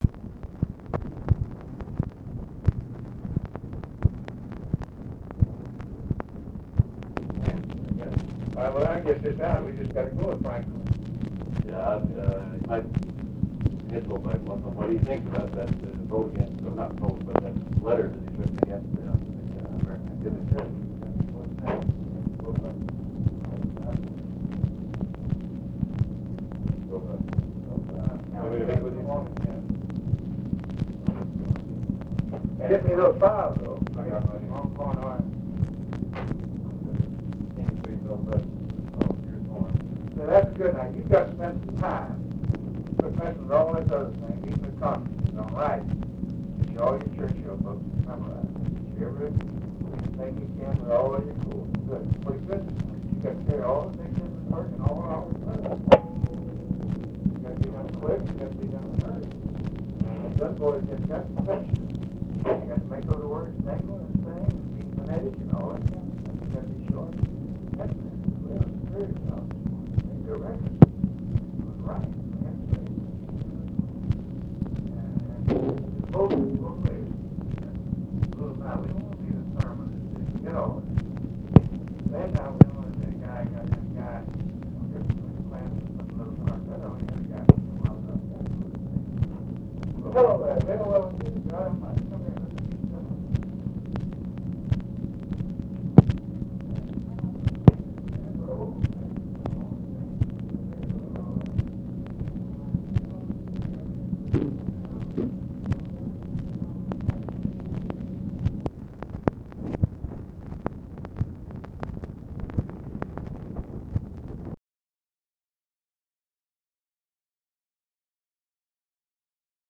OFFICE CONVERSATION, August 20, 1965
Secret White House Tapes